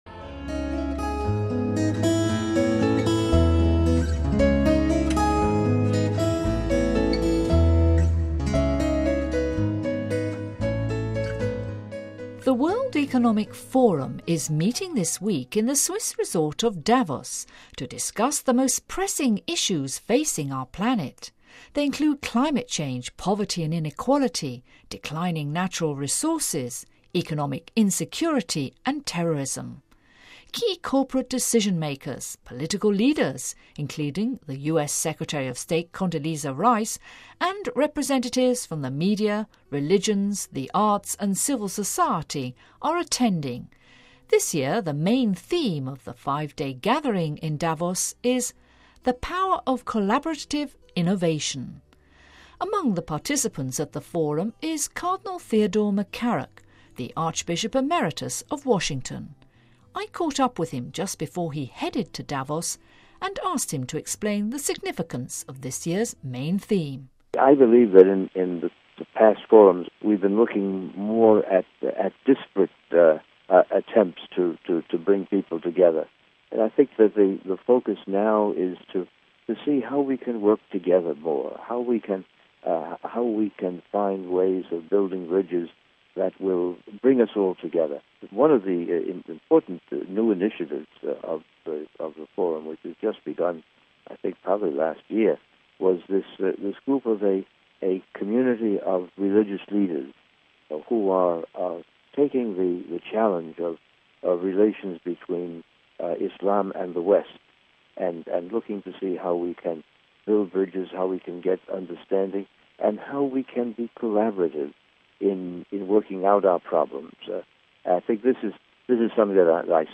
Home Archivio 2008-01-23 15:19:06 DISCUSSIONS AT DAVOS DISCUSSIONS AT DAVOS: Cardinal McCarrick shares with us his insights on the key issues being discussed at the World Economic Forum in Davos....